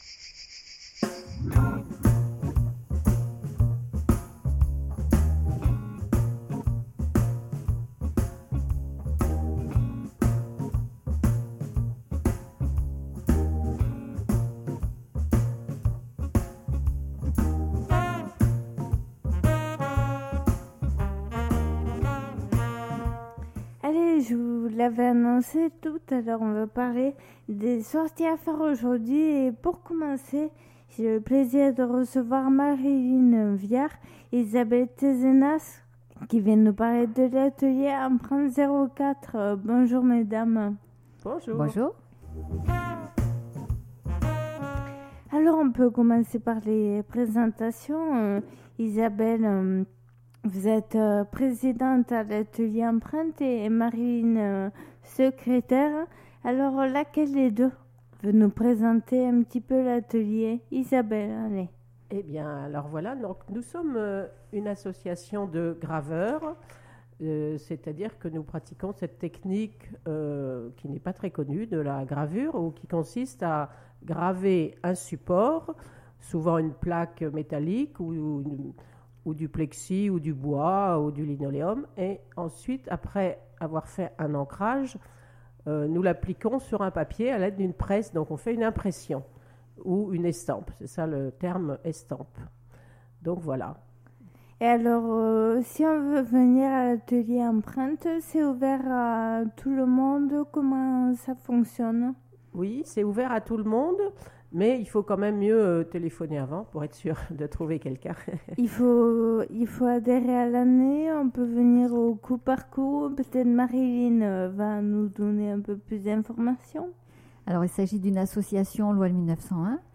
sont venues dans nos studios parler de l'exposition "Gravures en fêtes" qui a lieu en ce moment à Manosque et jusqu'à samedi 11 décembre.